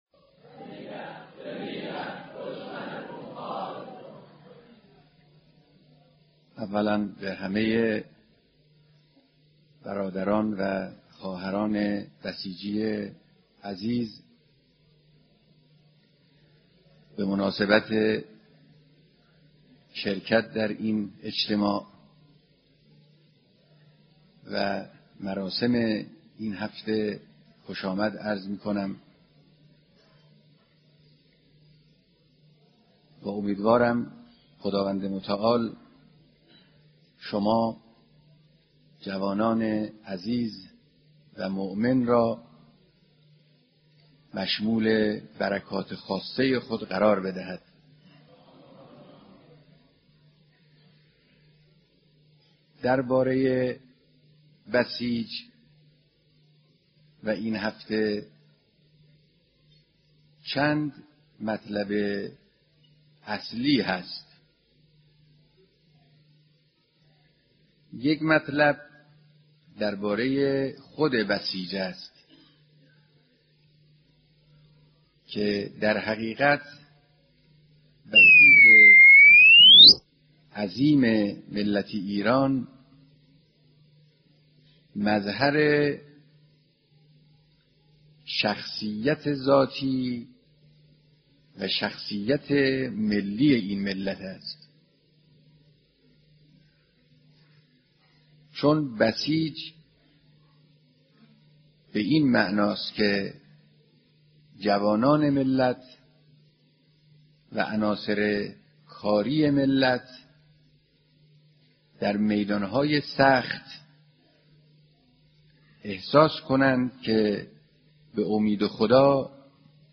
صوت کامل بیانات
سخنرانی